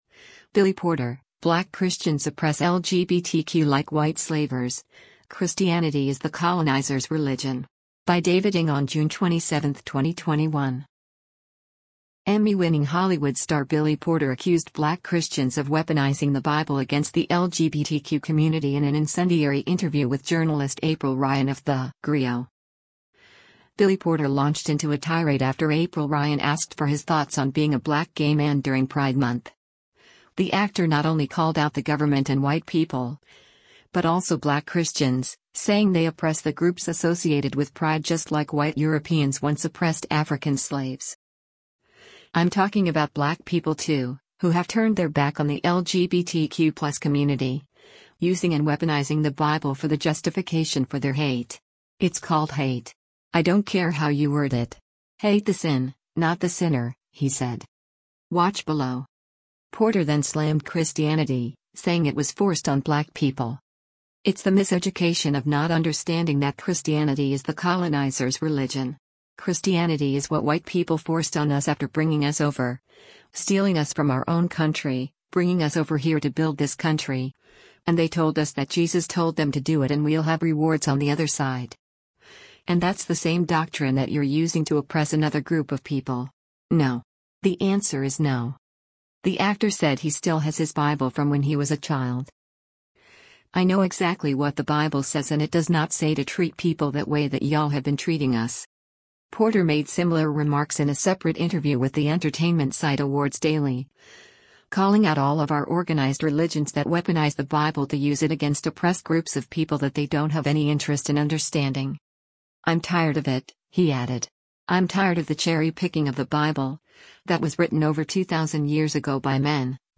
Emmy-winning Hollywood star Billy Porter accused black Christians of “weaponizing the Bible” against the LGBTQ community in an incendiary interview with journalist April Ryan of TheGrio.